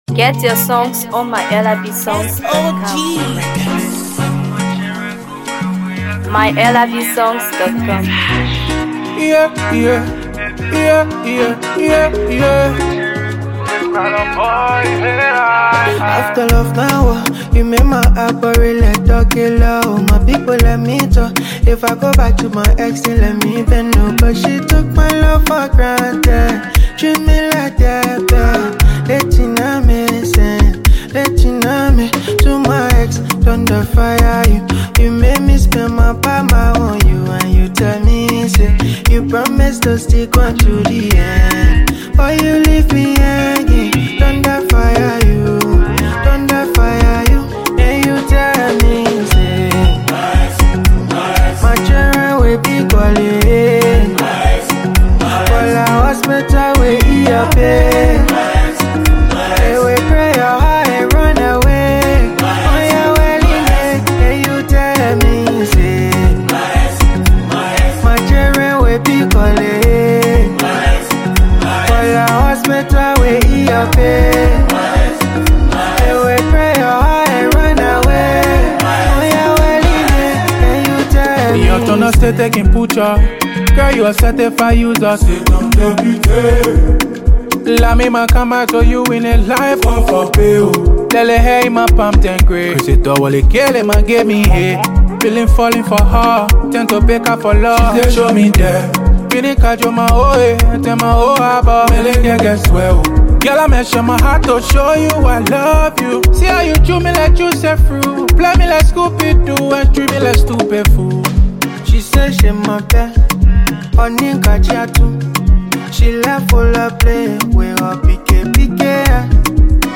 Afro PopMusic
Afrobeat flair